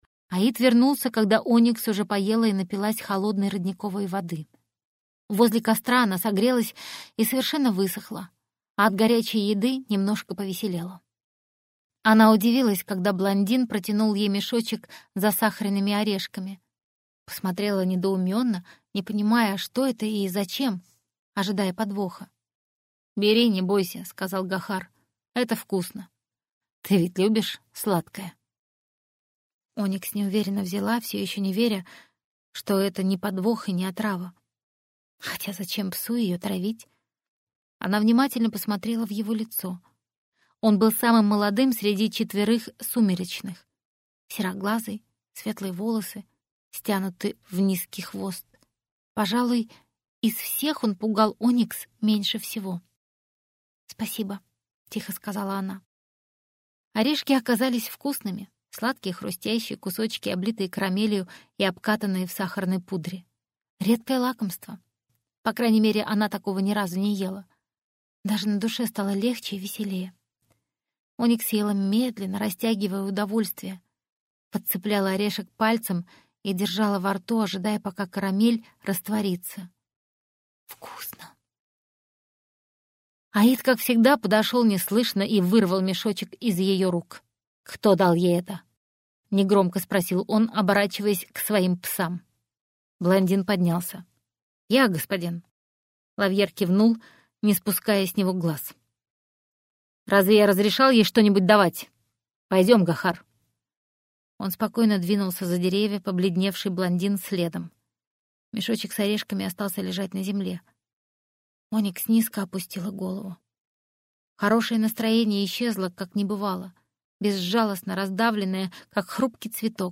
Аудиокнига Зачем цветет лори - купить, скачать и слушать онлайн | КнигоПоиск